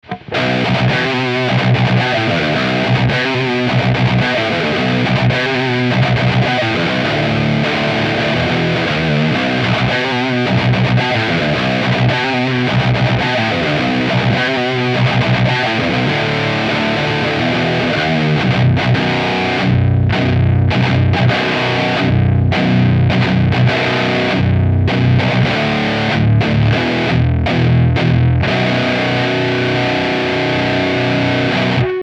- Canal lead, Fat 2, Depth 0, Presence 0 :
Les EQ sont à midi, et le gain du lead à 2-3 sur tous les samples.
Evil Eddie_Lead 4 V2 D0 P0.mp3